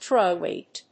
アクセントtróy wèight